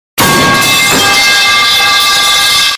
Âm thanh "Metal pipe clang" | Hiệu ứng âm thanh độc lạ ghép và chỉnh sửa video